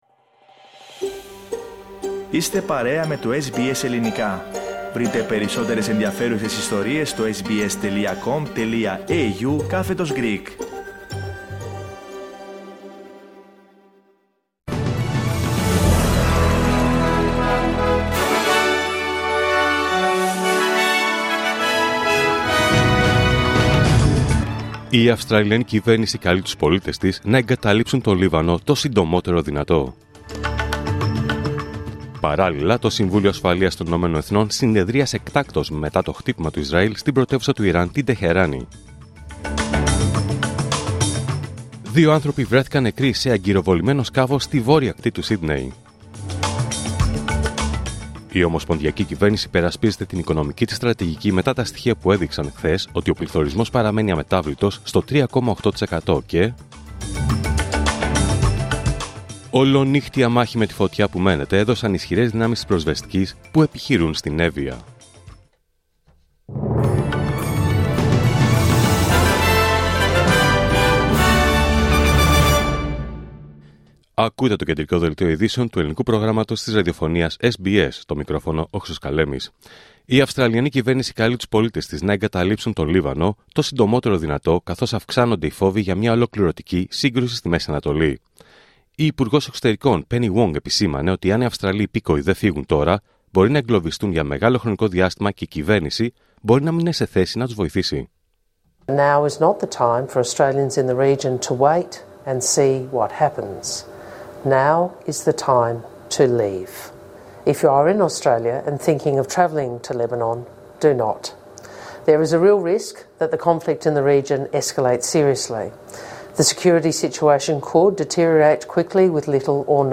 Δελτίο Ειδήσεων Πέμπτη 1 Αυγούστου 2024